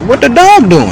drum-hitfinish.mp3